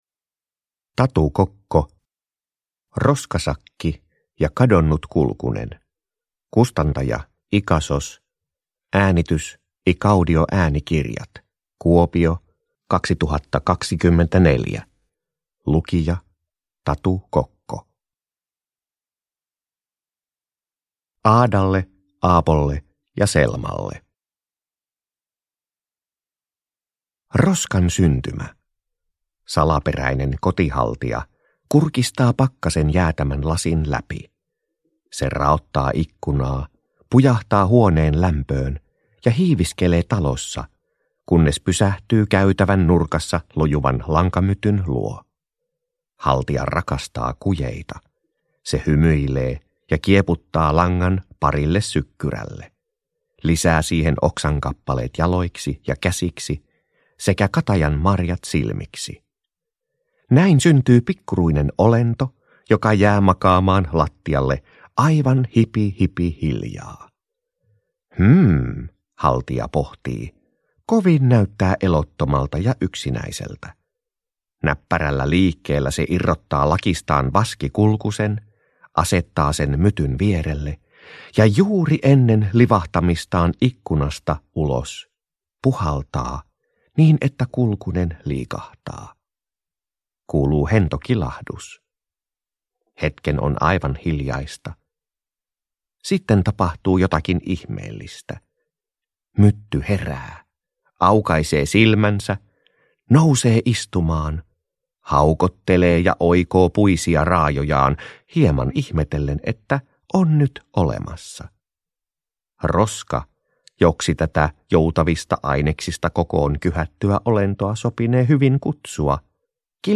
Roskasakki ja kadonnut kulkunen – Ljudbok